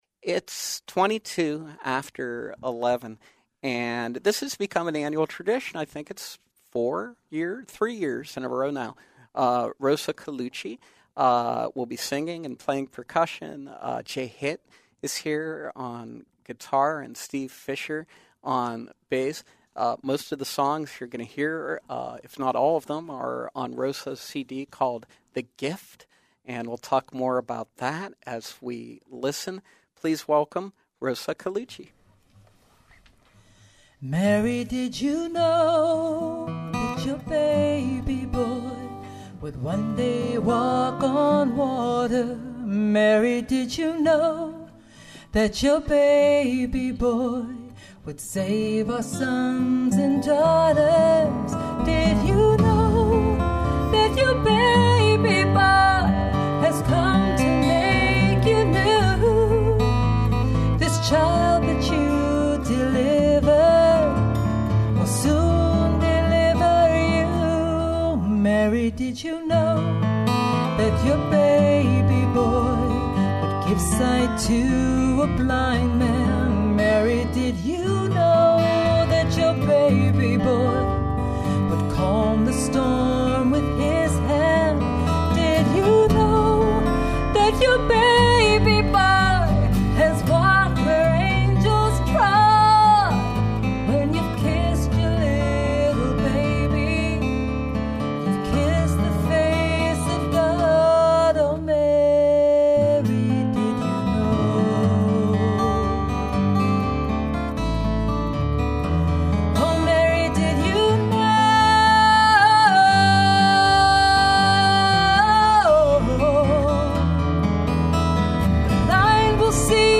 who will play some holiday favorites, live in our studios.